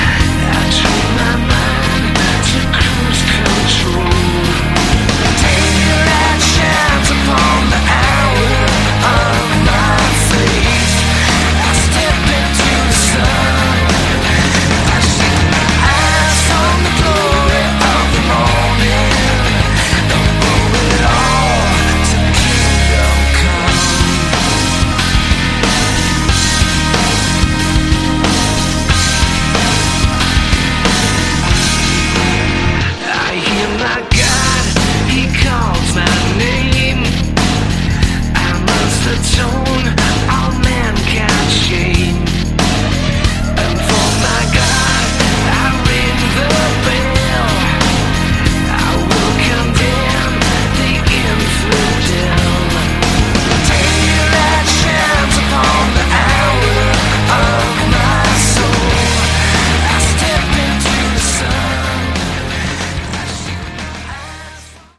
Category: Hard Rock / Melodic Rock